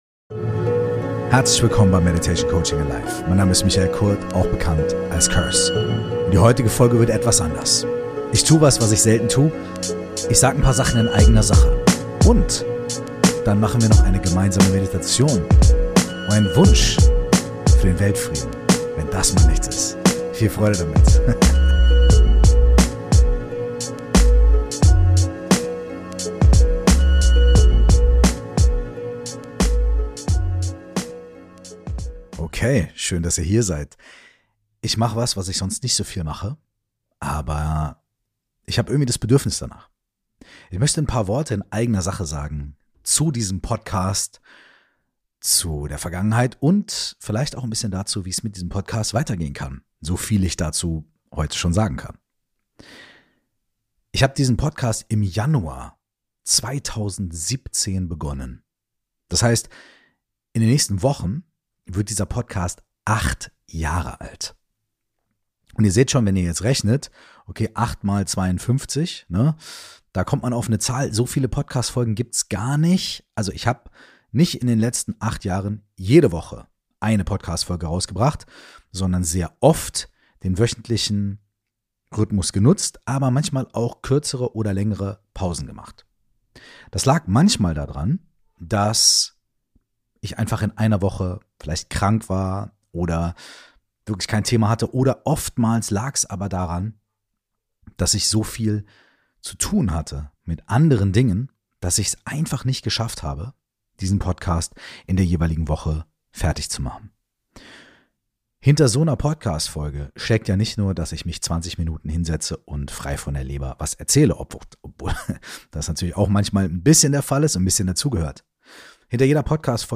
1 In eigener Sache - und für den Weltfrieden! 30:54 Play Pause 8h ago 30:54 Play Pause Später Spielen Später Spielen Listen Gefällt mir Geliked 30:54 366 In dieser letzten Podcast-Folge des Jahres 2024 gibt's ein paar persönliche Worte von Curse und ein einige Insider zur Geschichte des Podcasts. Als kleines nachträgliches Weihnachtsgeschenk gibt's noch eine Meditation on top.